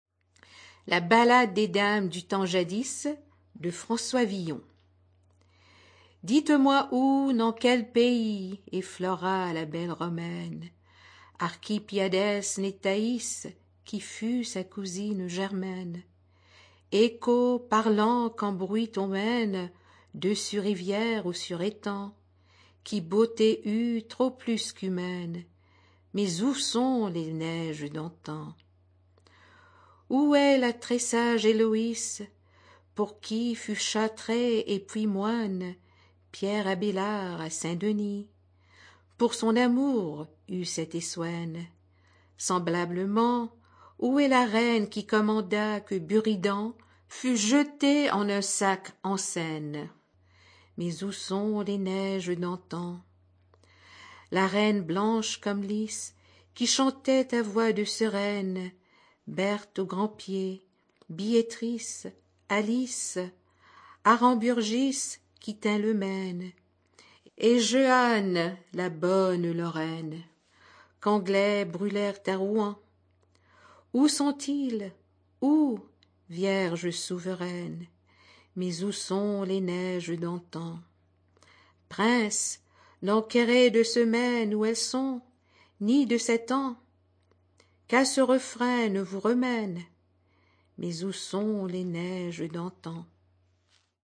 Audio non-musical
poetry